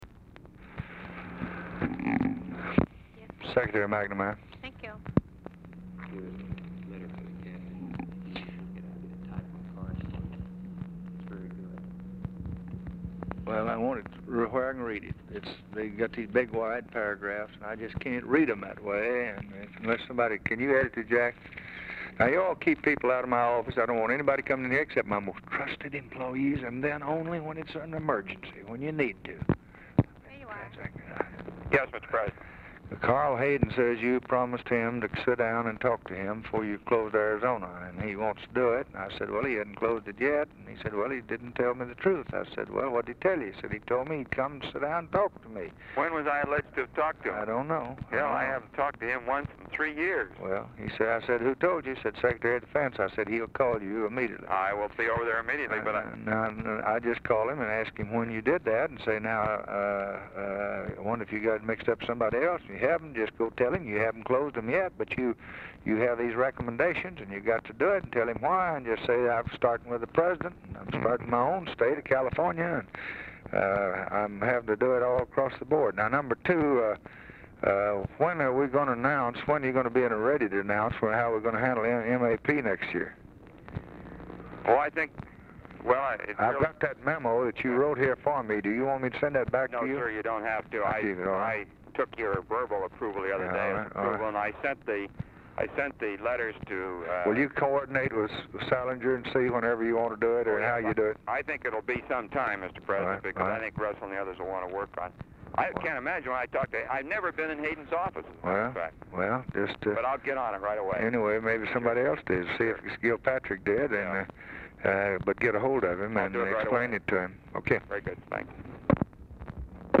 OFFICE CONVERSATION PRECEDES CALL; LBJ TALKS WITH UNIDENTIFIED MEN (BILL MOYERS AND JACK VALENTI?) ABOUT EDITING STATEMENTS, KEEPING PEOPLE OUT OF HIS OFFICE
Format Dictation belt
Specific Item Type Telephone conversation